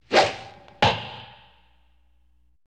Звуки лука, стрел
Продолжительный звук стрелы в полете